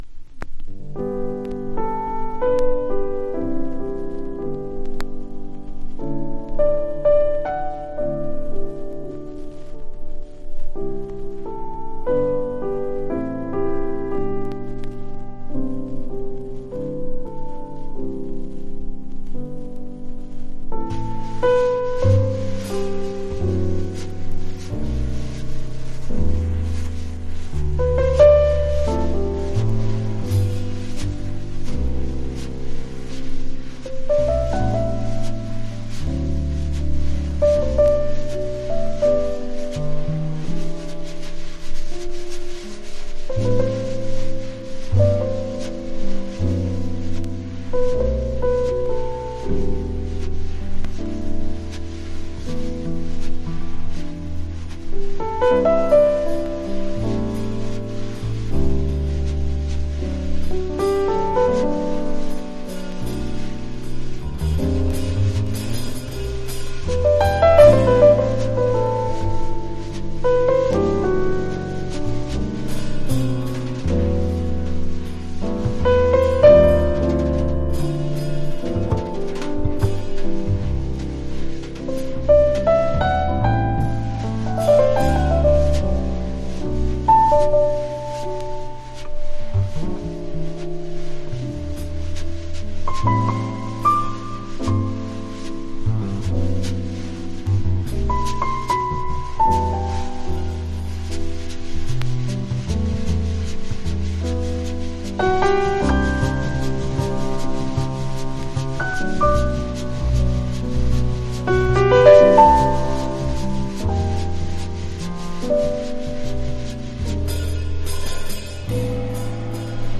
トリオものLive好内容盤